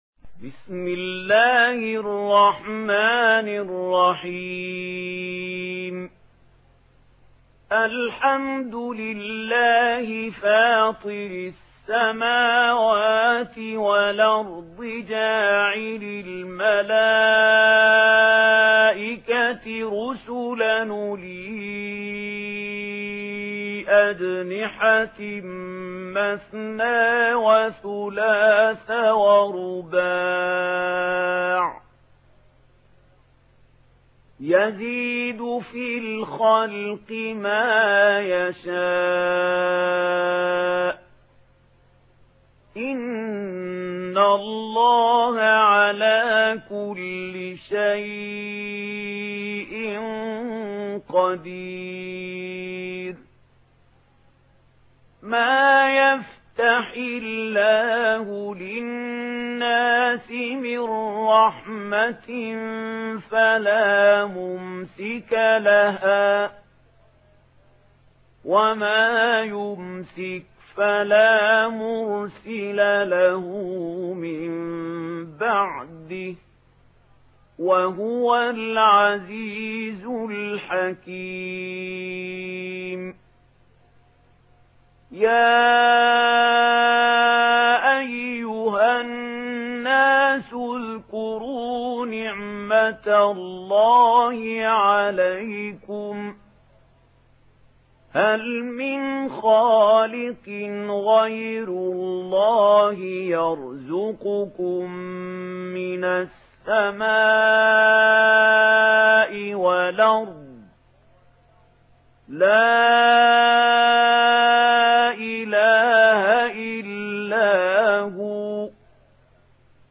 Une récitation touchante et belle des versets coraniques par la narration Warsh An Nafi.